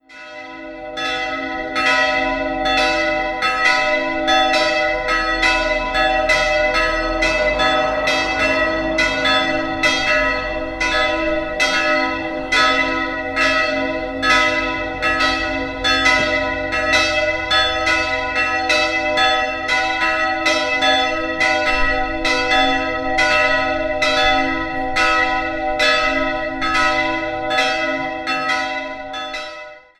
Im Kern noch aus dem 13. Jahrhundert stammt die kleine Pfarrkirche Mariä Himmelfahrt. Im Jahr 1734 wurde sie von Balthasar Suiter umgestaltet. 2-stimmiges Geläute: h'-dis'' Die größere Glocke wurde 1865 von Boehm in Lauingen gegossen, die kleinere 1947 bei Kuhn-Wolfart, ebenfalls in Lauingen.